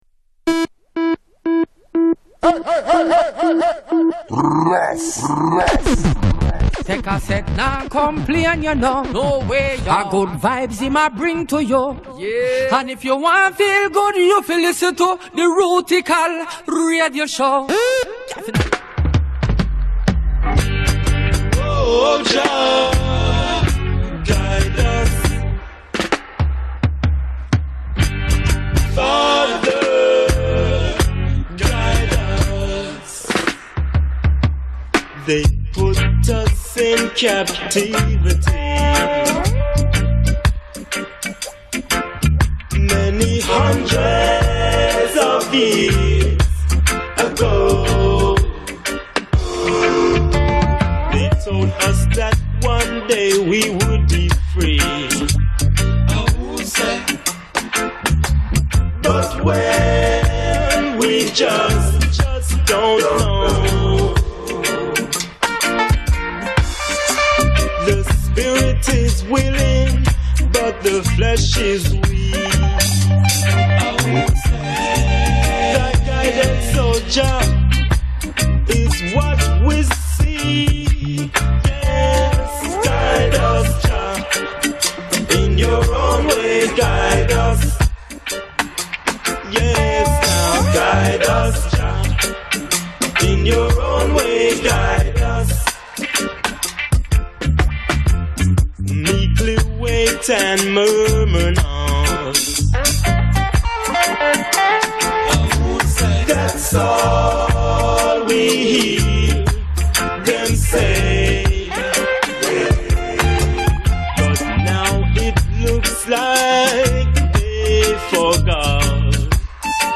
Finest in Roots, Dub, Stepper and Bass Culture